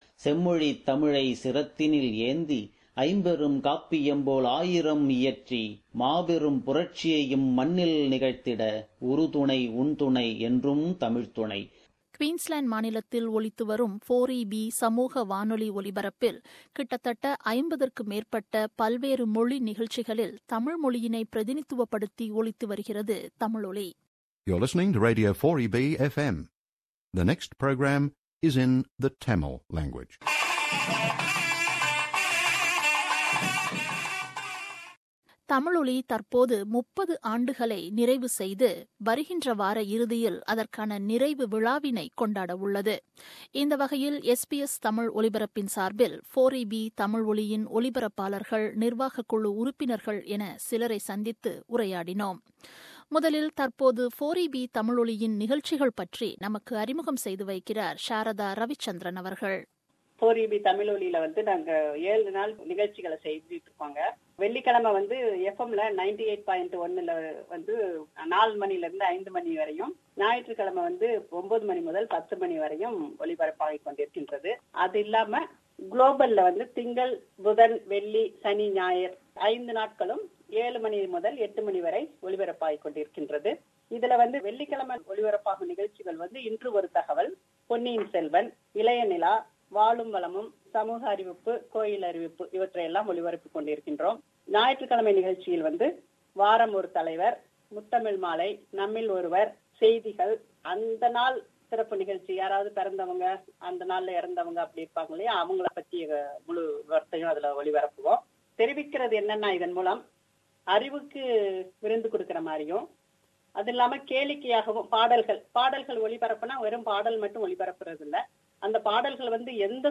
4EB Tamil Oli is celebrating its 30th anniversary. This feature is a discussion about 4EB Tamil Oli with its broadcasters and committee members.